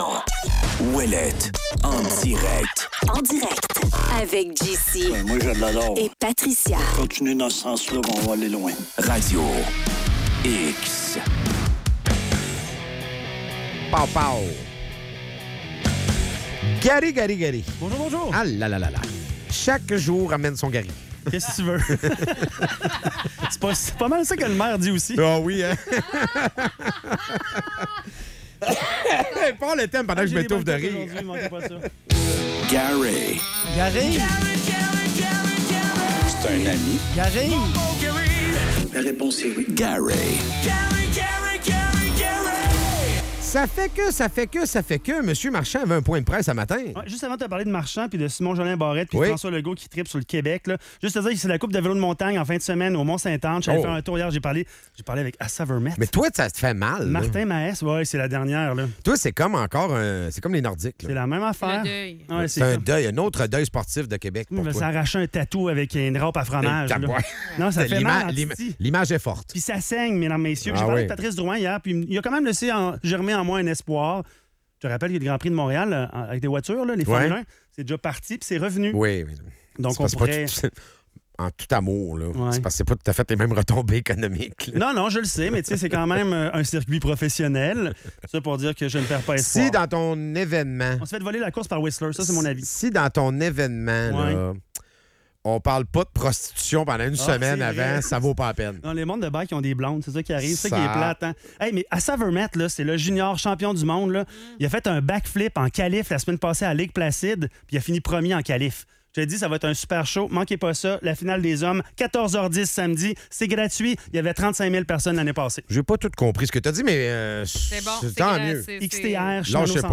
Point de presse de mi-mandat pour Bruno Marchand